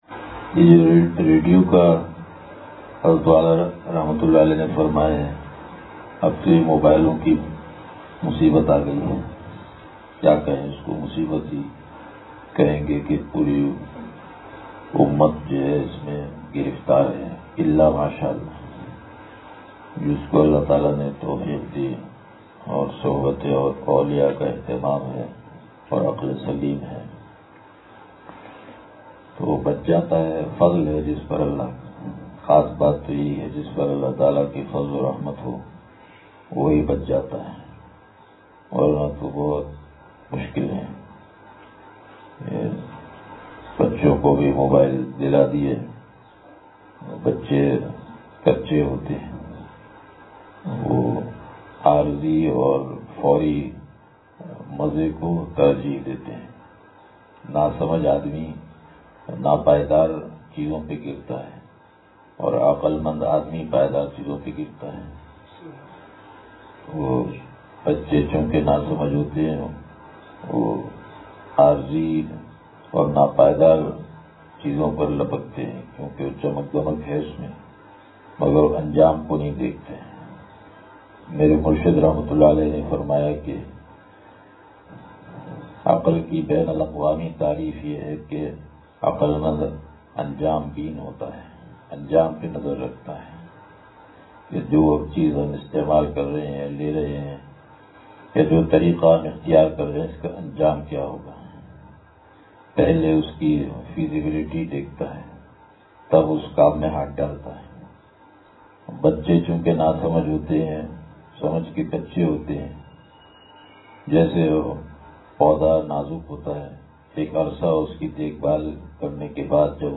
بیان – اتوار